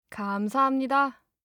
알림음 8_감사합니다3-여자.mp3